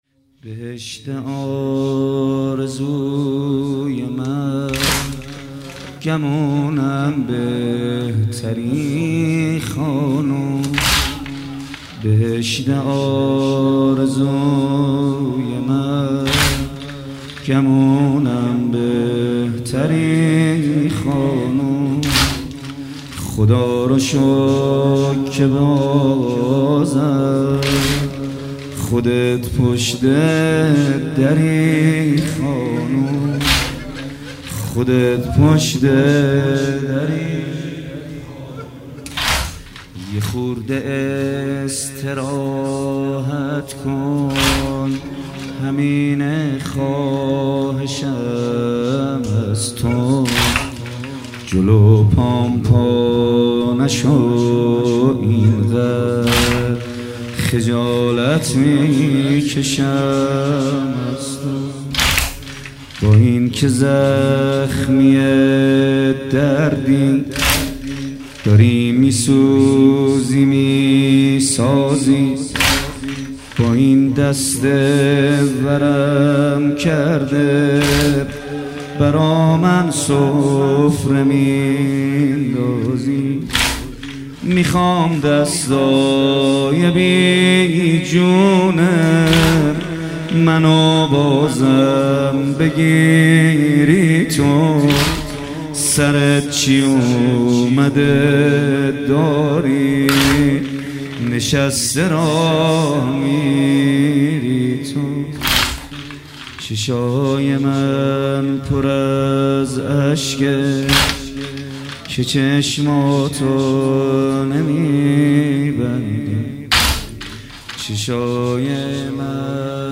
مداحی جدید
ایام فاطمیه اول هیات ریحانة النبی(س)